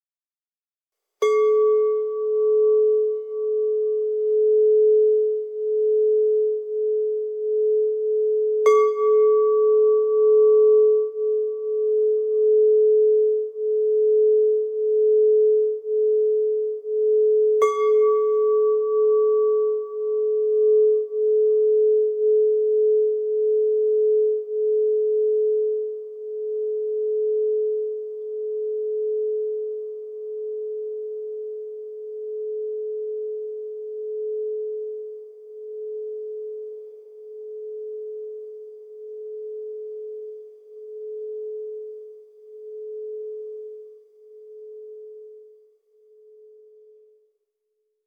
Meinl Sonic Energy Sound Bath Tube Chime Chakra Set, 432 Hz, Schwarz - 7 teilig (SBTSETCHA)
Bereichere deine Klangreisen mit dem Meinl Sonic Energy Sound Bath Tube Chime Chakra Set, das aus sieben Tönen besteht.